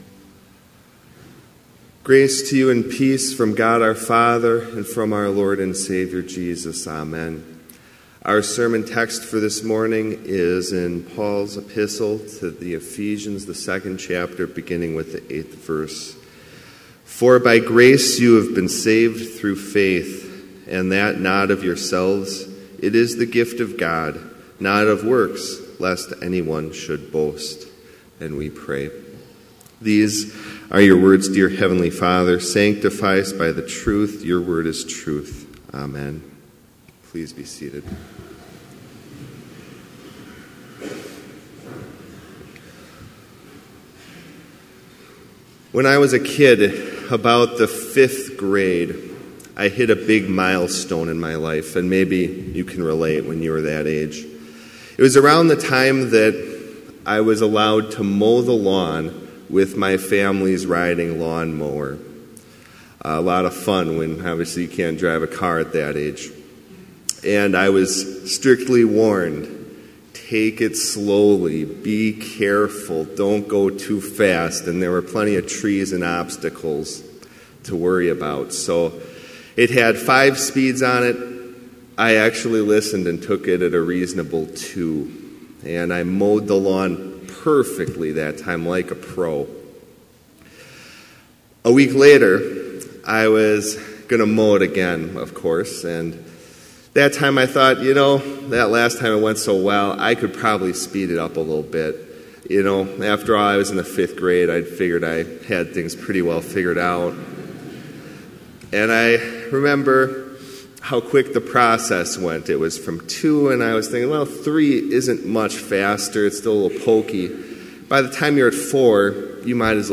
Complete service audio for Chapel - February 13, 2017